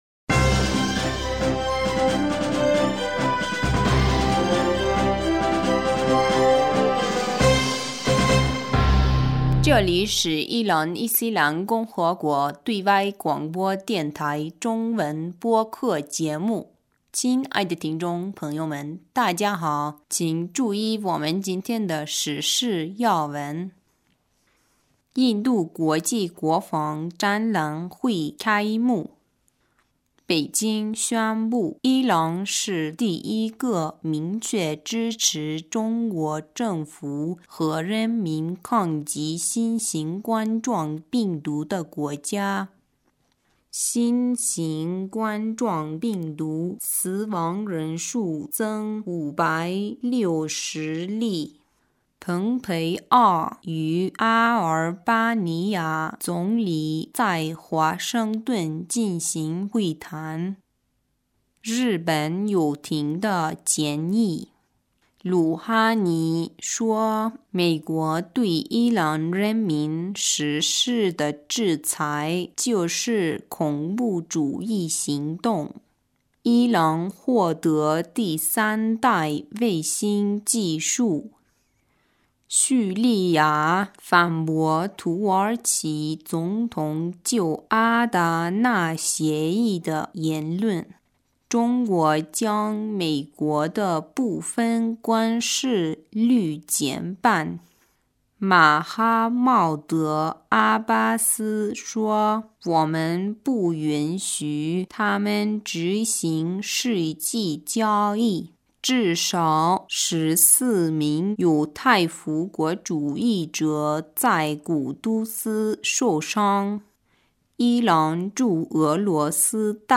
2020 年2月6日 新闻